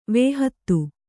♪ vēhattu